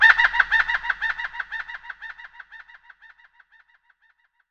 Category 🌿 Nature
ambient animal bird birdsong delay dub echo effect sound effect free sound royalty free Nature